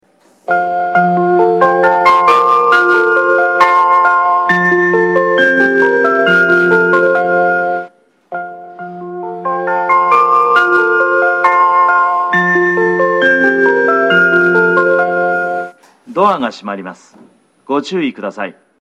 スピーカーは小ボ スが設置されており音質は高音質です。
１番線八高線
発車メロディー1.9コーラスです。